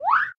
minecraft / sounds / mob / parrot / idle3.ogg